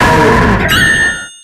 234f22e78e80a3ebbc470448ae22d9e5e5c0402b infinitefusion-e18 / Audio / SE / Cries / BASTIODON.ogg infinitefusion d3662c3f10 update to latest 6.0 release 2023-11-12 21:45:07 -05:00 14 KiB Raw History